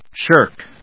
shirk /ʃˈɚːkʃˈəːk/
• / ʃˈɚːk(米国英語)
• / ʃˈəːk(英国英語)